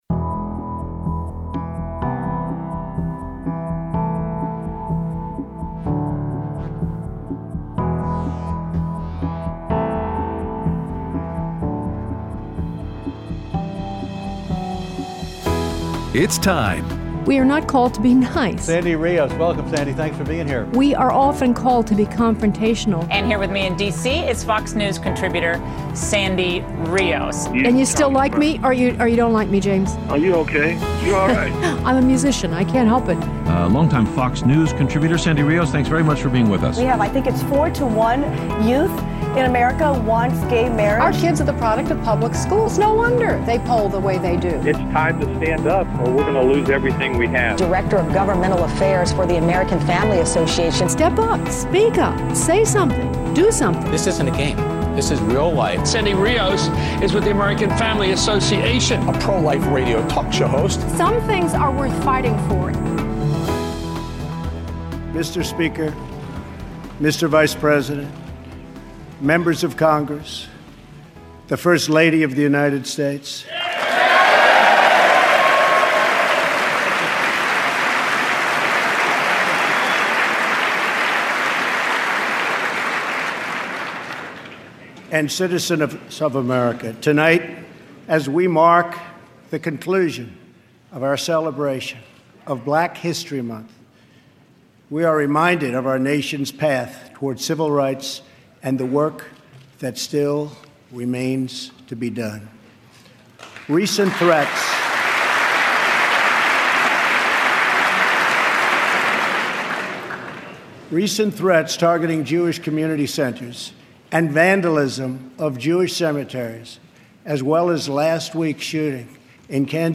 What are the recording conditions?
Aired Wednesday 3/1/17 on AFR 7:05AM - 8:00AM CST